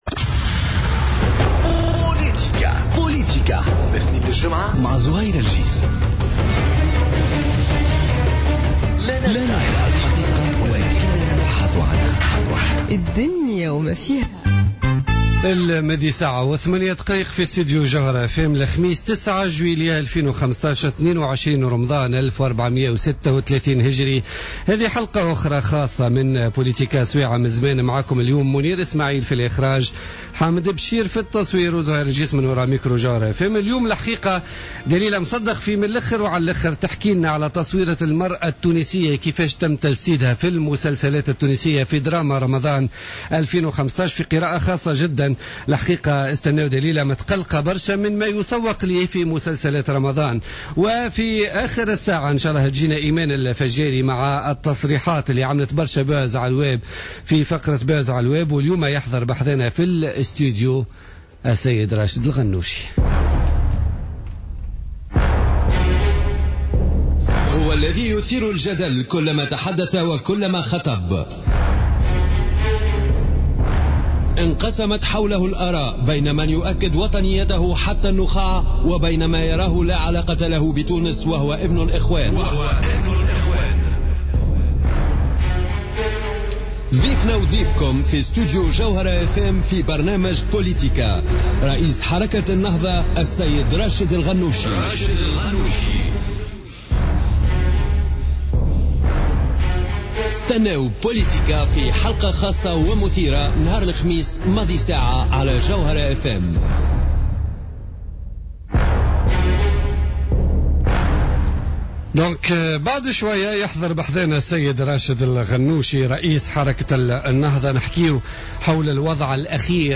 حوار خاص مع رئيس حركة النهضة راشد الغنوشي